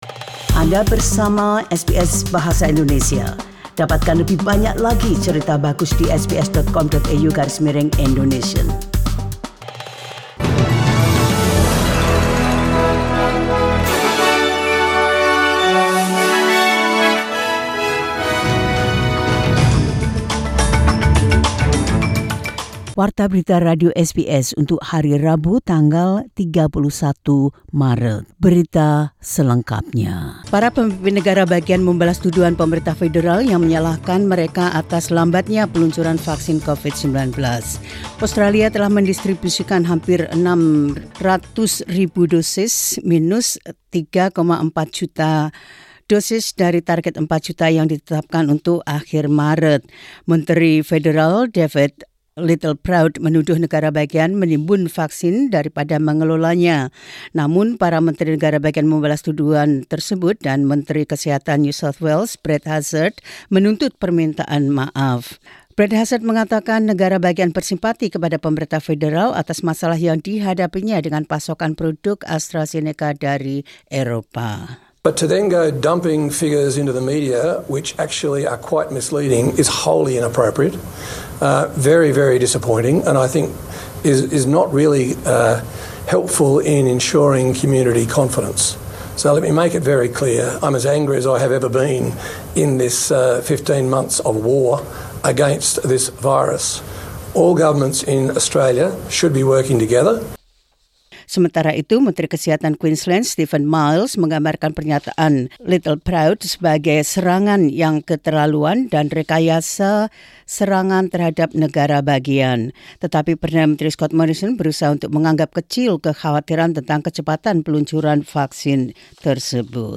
Warta Berita Radio SBS Program Bahasa Indonesia – 31 Mar 2021.